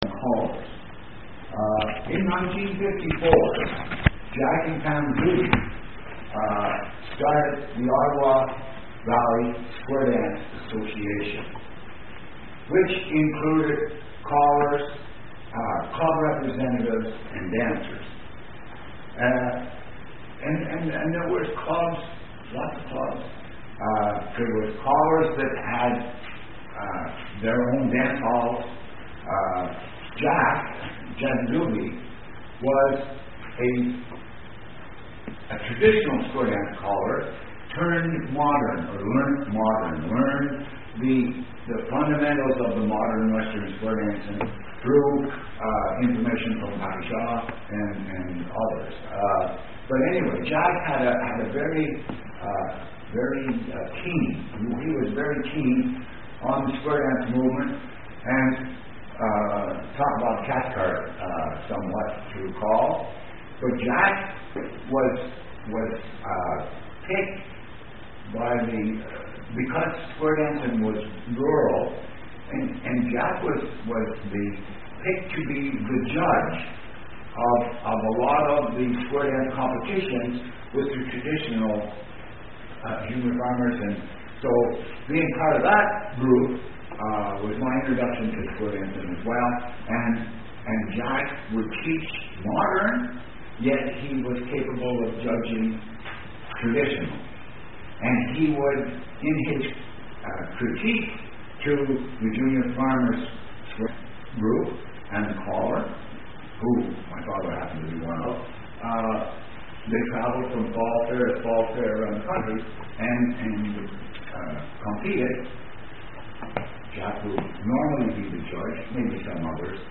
Presentations